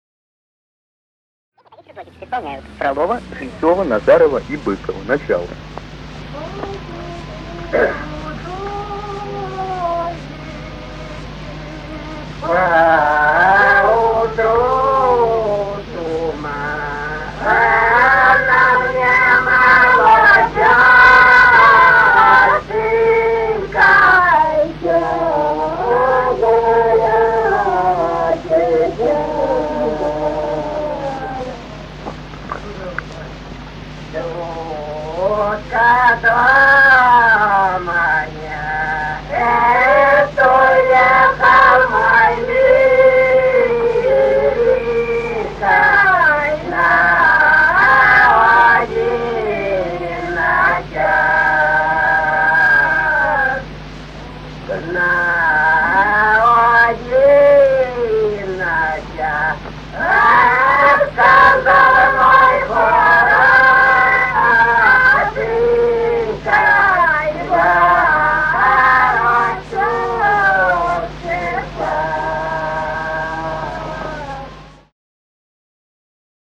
Русские народные песни Владимирской области 29. По вечеру дождик (лирическая) с. Михали Суздальского района Владимирской области.